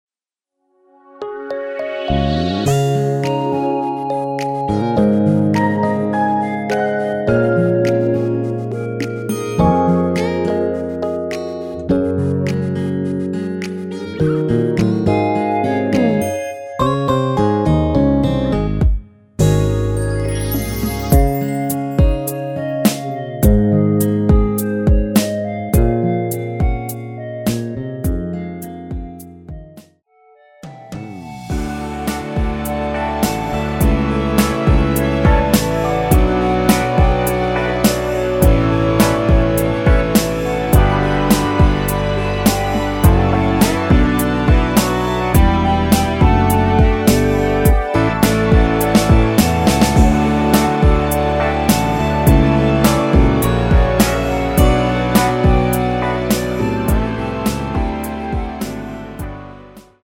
Db
◈ 곡명 옆 (-1)은 반음 내림, (+1)은 반음 올림 입니다.
음정과 박자 맞추기가 쉬워서 노래방 처럼 노래 부분에 가이드 멜로디가 포함된걸
앞부분30초, 뒷부분30초씩 편집해서 올려 드리고 있습니다.